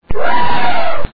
Animal Sound Effects
The sound bytes heard on this page have quirks and are low quality.
ELEPHANT'S BLARE 0.99